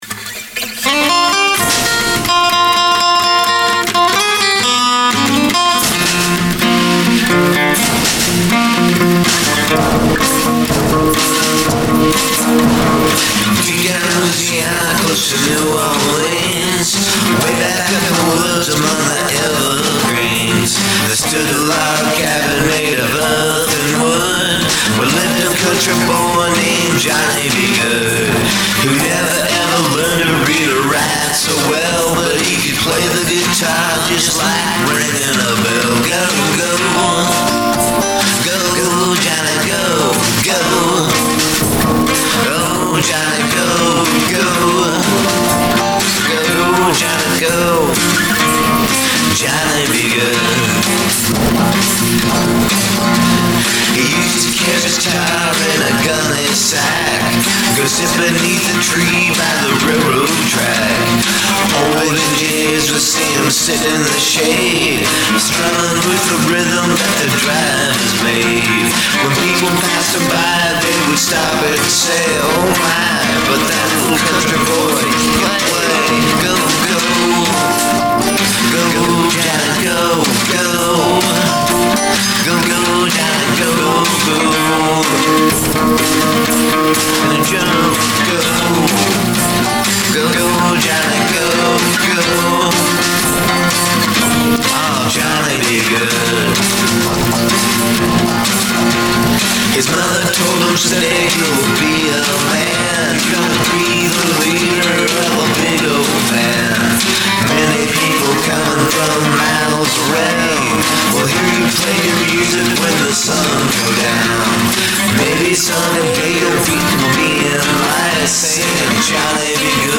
cover
I was hesitant because I am more of rhythm guitar player.
The lead tho, sounds better in the original key.